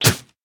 Minecraft Version Minecraft Version snapshot Latest Release | Latest Snapshot snapshot / assets / minecraft / sounds / mob / llama / spit1.ogg Compare With Compare With Latest Release | Latest Snapshot
spit1.ogg